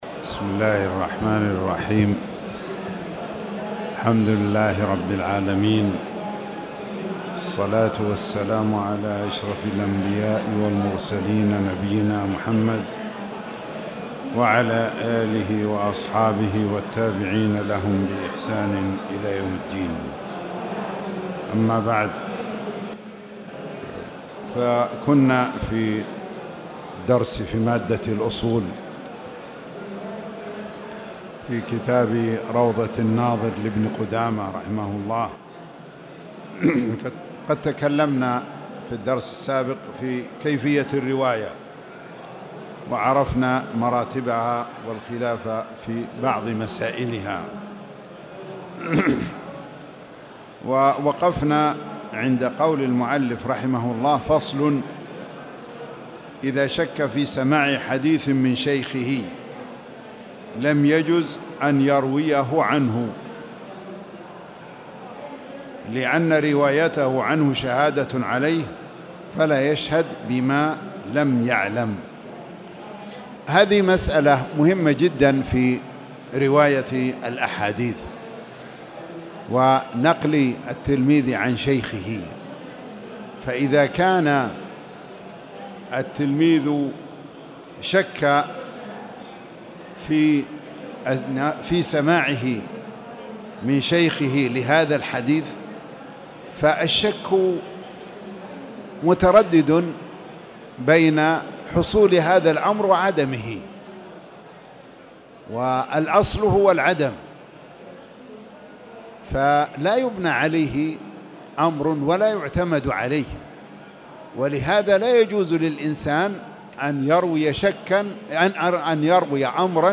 تاريخ النشر ١٠ محرم ١٤٣٨ هـ المكان: المسجد الحرام الشيخ